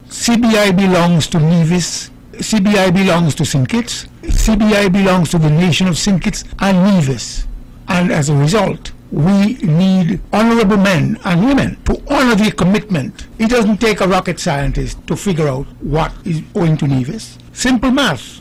Panelist comments on St. Kitts-Nevis CBI program